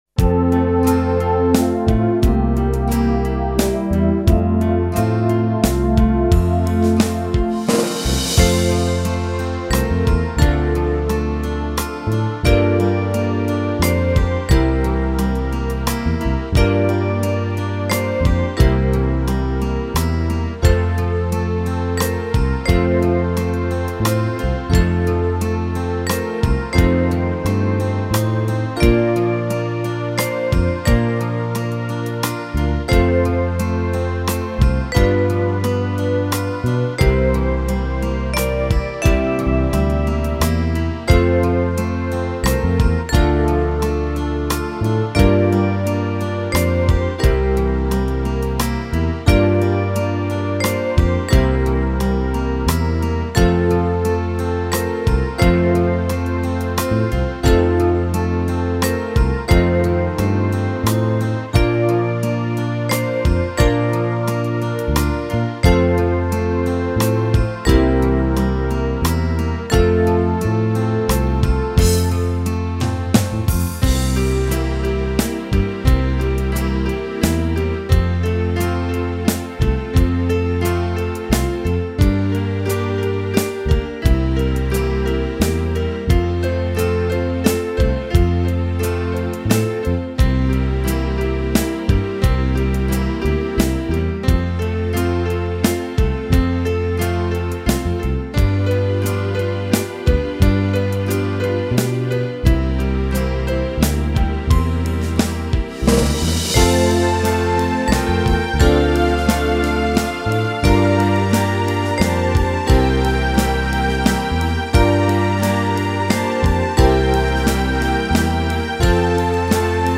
Boston